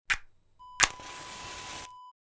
Striking a Match Wav Sound Effect #5
Description: The sound of striking a match against the side of a matchbox and lighting it
Properties: 48.000 kHz 16-bit Stereo
A beep sound is embedded in the audio preview file but it is not present in the high resolution downloadable wav file.
match-striking-preview-5.mp3